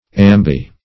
Meaning of ambi-. ambi- synonyms, pronunciation, spelling and more from Free Dictionary.